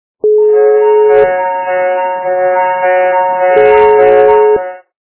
» Звуки » Авто, мото » Сирена - японских ментов
При прослушивании Сирена - японских ментов качество понижено и присутствуют гудки.
Звук Сирена - японских ментов